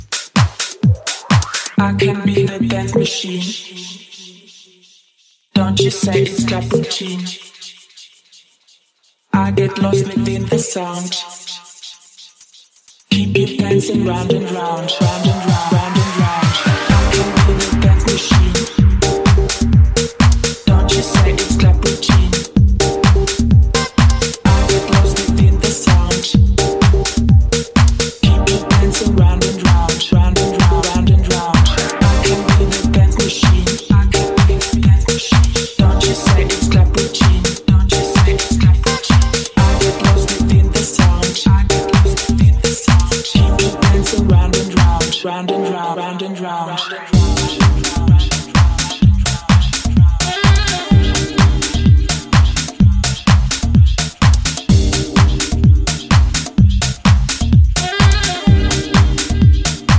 程良いバレアリック感でフロアをジワリと熱するプログレッシヴ・ハウス